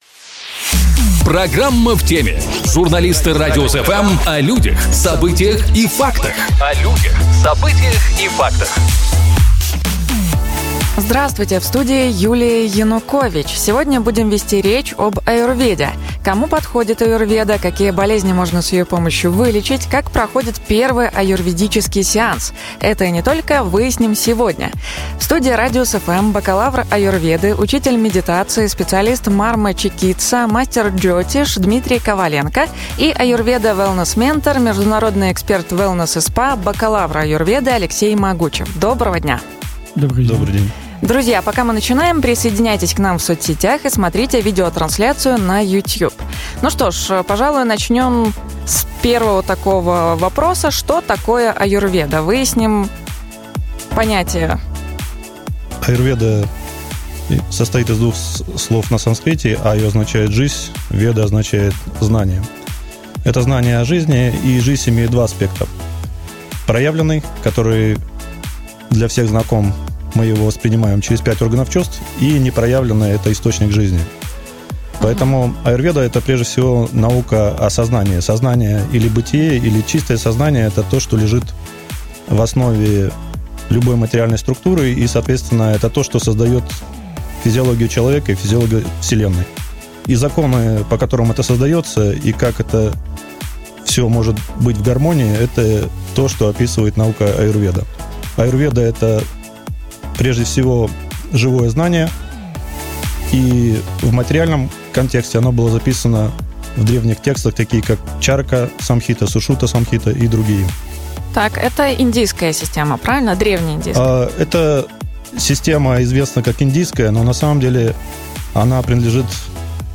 В студии